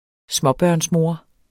Udtale [ ˈsmʌbɶɐ̯ns- ]